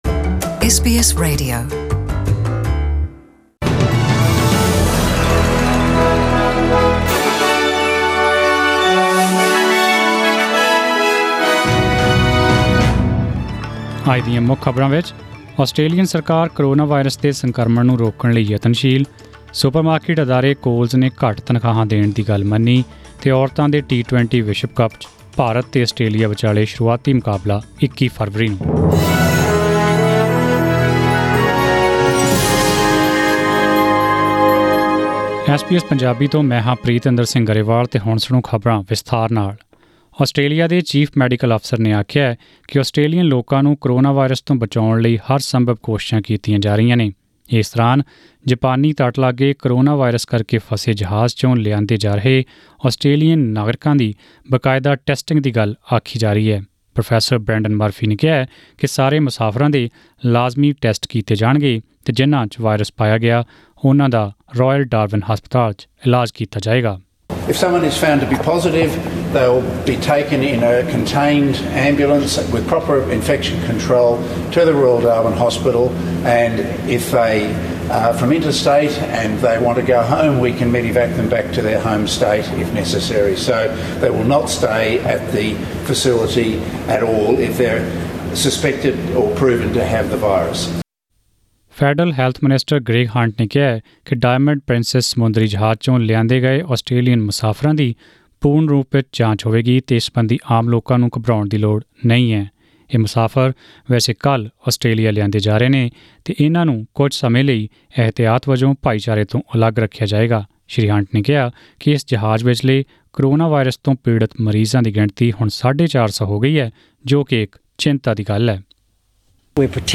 Australian News in Punjabi: 18 February 2020